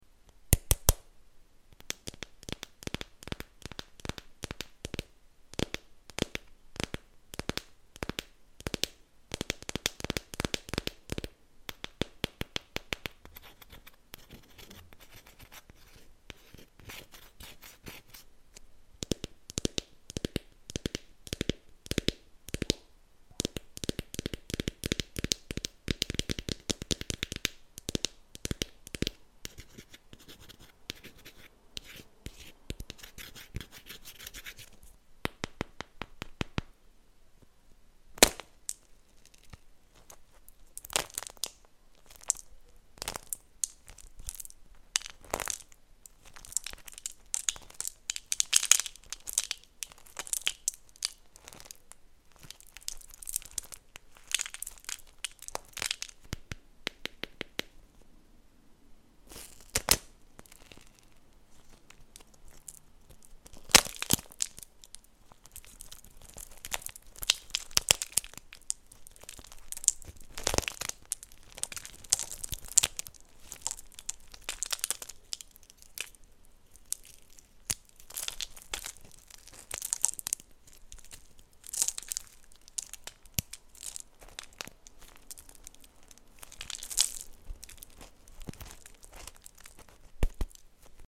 wax cracking asmr watermelon squishy sound effects free download